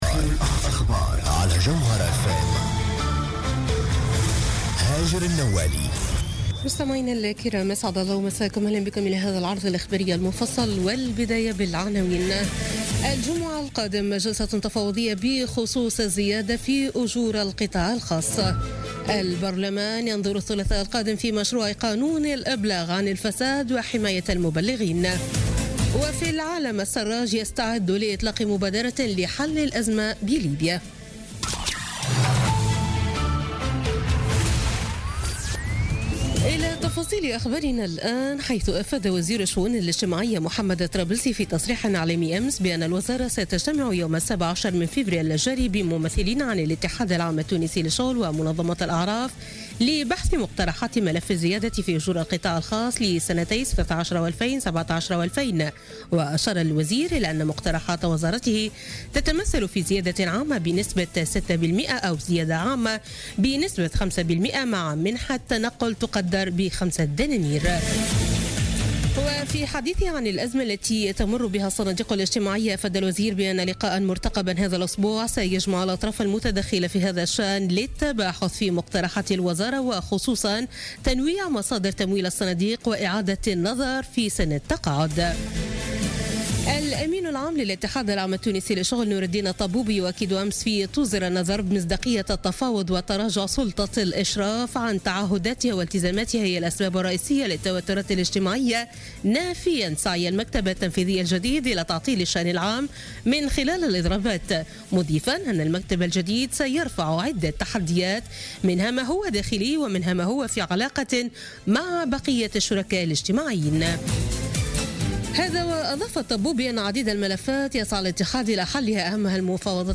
نشرة أخبار منتصف الليل ليوم الإثنين 13 فيفري 2014